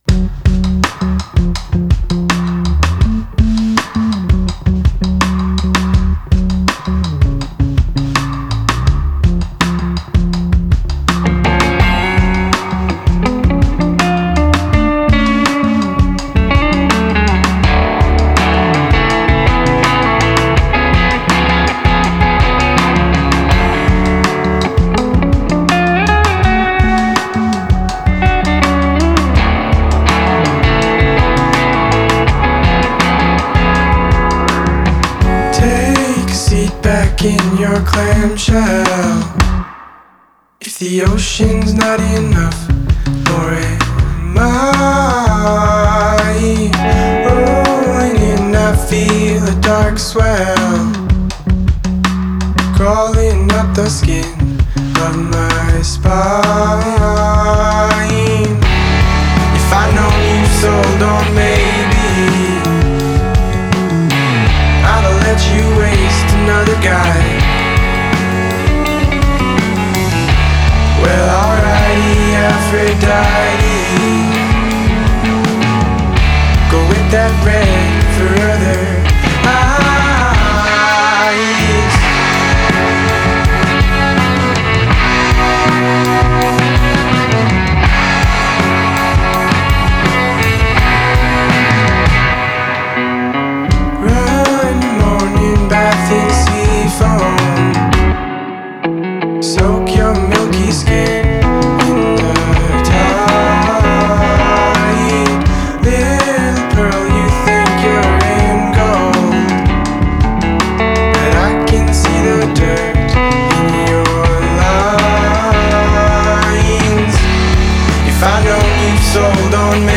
Alternative Rock
Indie Rock